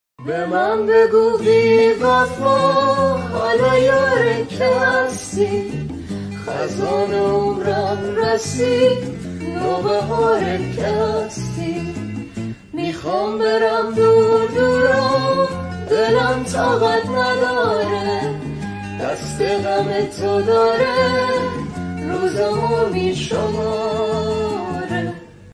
صدای دختر اینستا